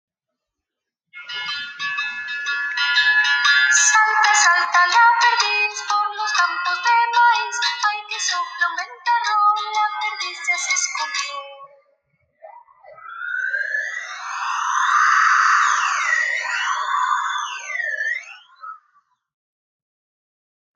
AUDIOCUENTO BASADO EN LA CANCION DE JUDITH AKOSCHKY